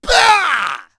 Ezekiel-Vox_Attack4.wav